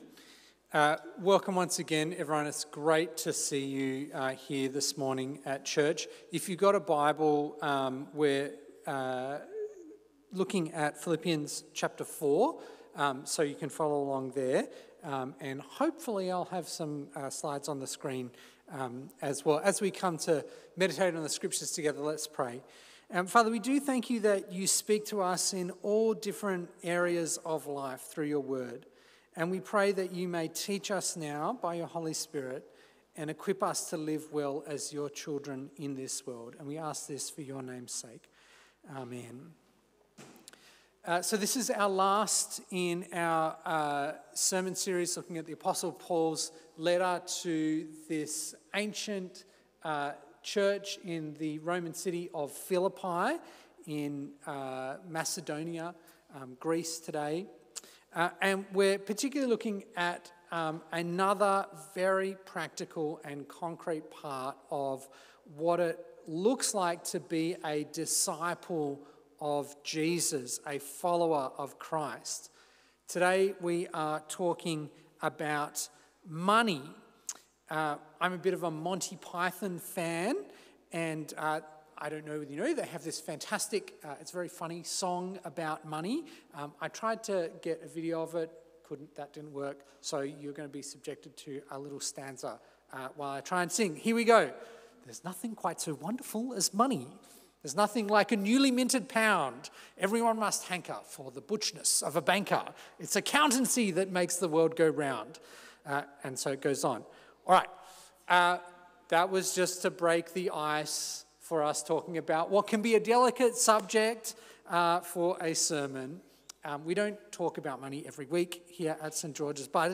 A sermon on Philippians 4:10-20